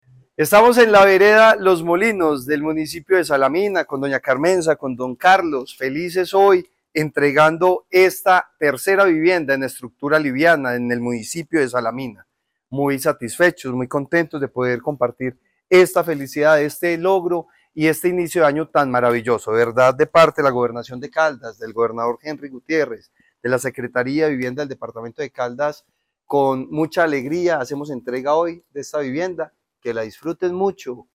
Francisco Javier Vélez Quiroga, secretario de Vivienda y Territorio.
FRANCISCO-JAVIER-VELEZ-QUIROGA-Secretario-de-vivienda-de-Caldas-Entrega-de-vivienda-Salamina-.mp3